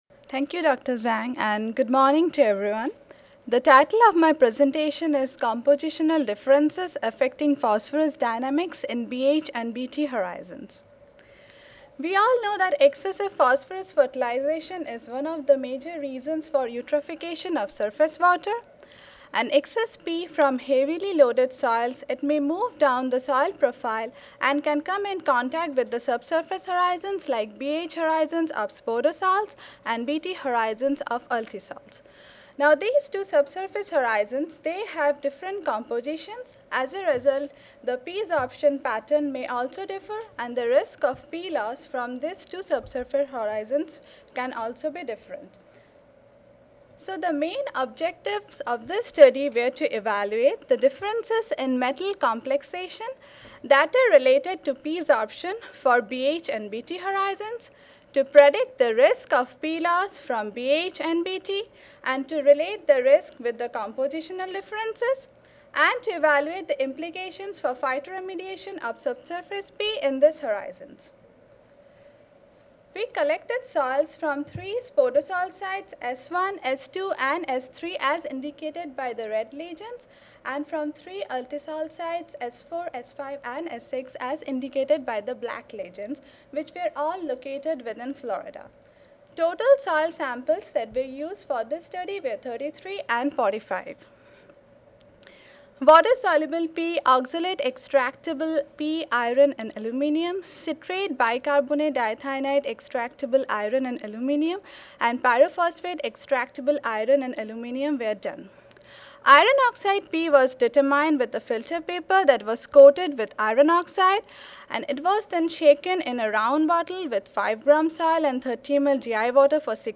University of Florida Audio File Recorded presentation